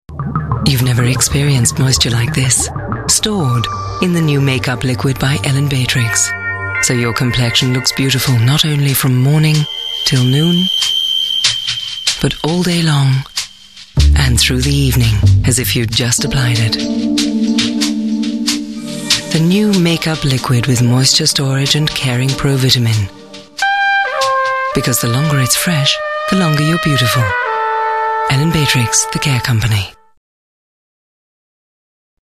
englische Synchron-Sprecherin.
Sprechprobe: Werbung (Muttersprache):
english female voice over artist